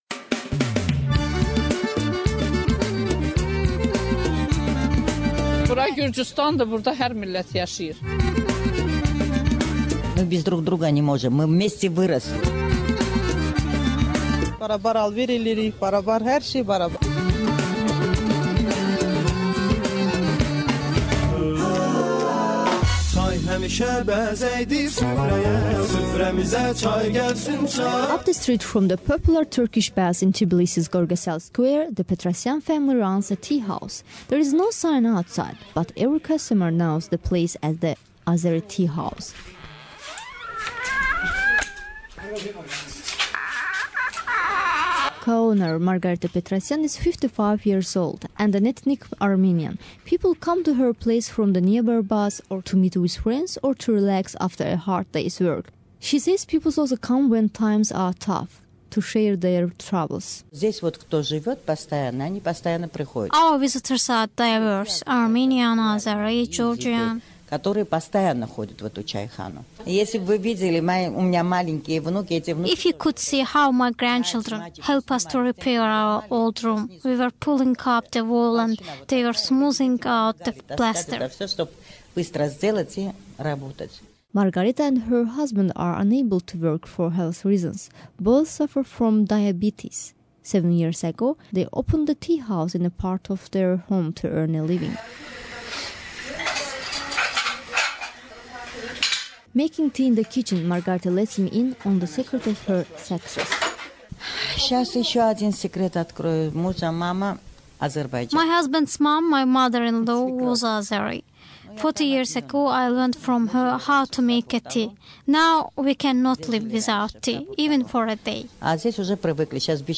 The same appeared to hold in the teahouses here and in Tbilisi. Everywhere we went, questions about the relationship among ethnic groups were met with puzzlement and surprise.